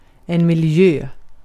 Ääntäminen
Synonyymit umbworld surroundings Ääntäminen UK US : IPA : [ɪn.ˈvaɪ.ɹən.mənt] Tuntematon aksentti: IPA : /ɪnˈvaɪɹə(n)mɪnt/ IPA : /ɪnˈvaɪɚ(n)mɪnt/ IPA : /ɪn.ˈvɑɪ.rən.mənt/ Lyhenteet ja supistumat (laki) Env't